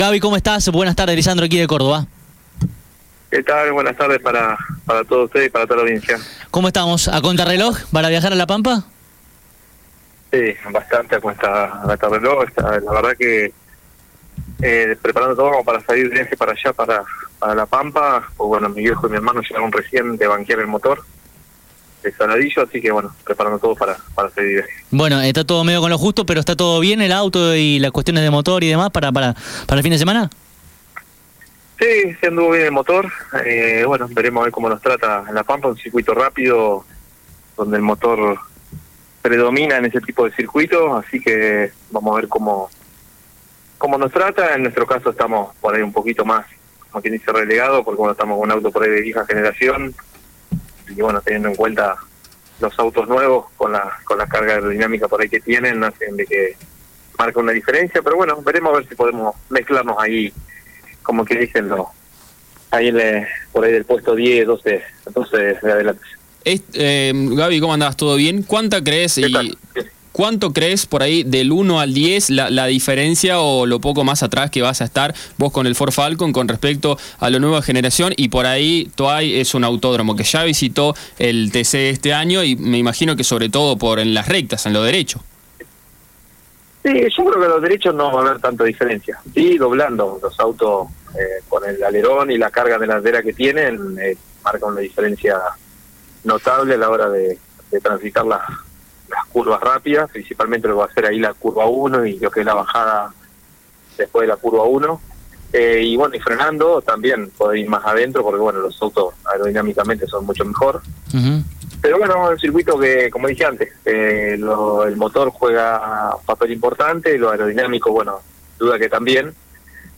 Previo a la segunda visita del año del Turismo de Carretera a La Pampa, Gabriel Ponce de León dialogó con CÓRDOBA COMPETICIÓN.